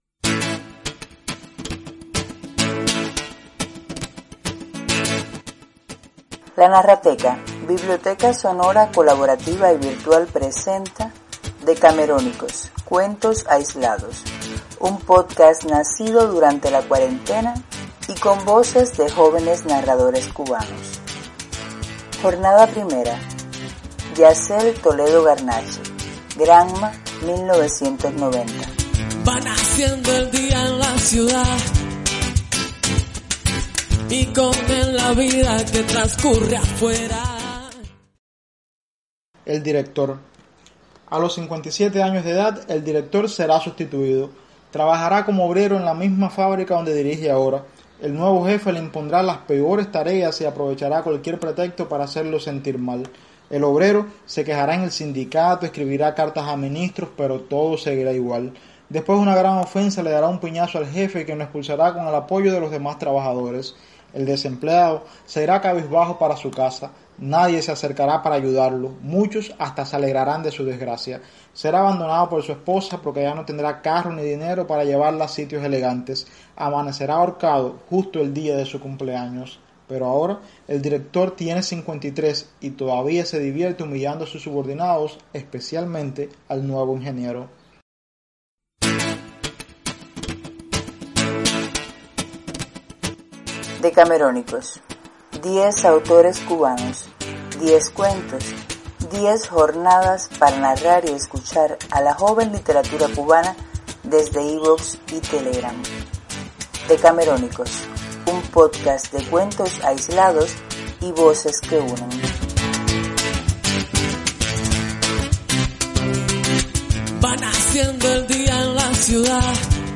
da voz a 10 jóvenes autores cubanos con 10 relatos breves en 10 jornadas.
Texto y voz.